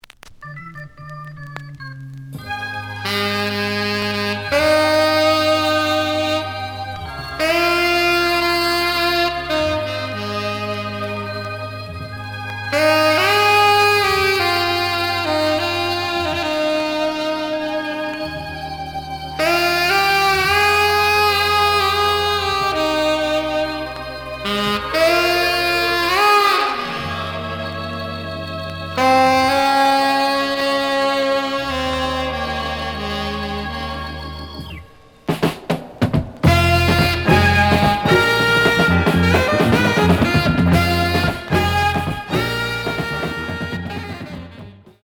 The audio sample is recorded from the actual item.
●Genre: Funk, 70's Funk
B side plays good.)